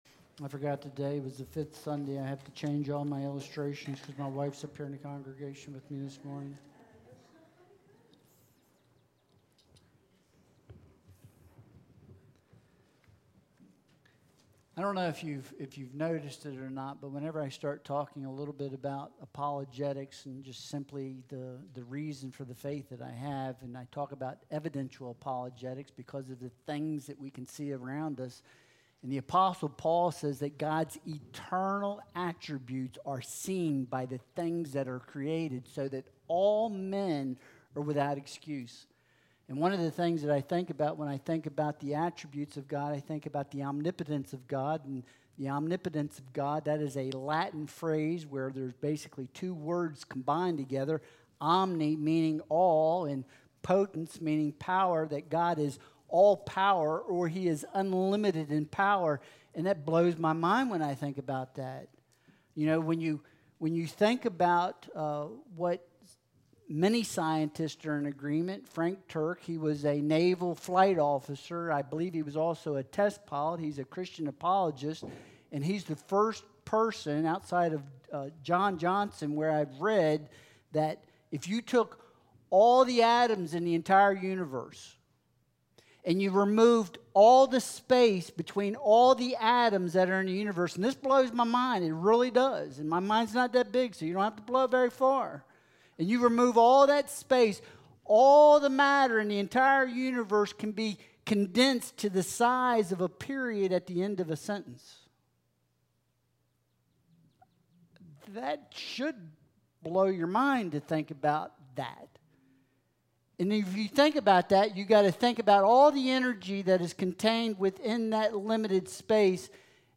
Sermon series: The Attributes of God – Omnipotent
Service Type: Sunday Worship Service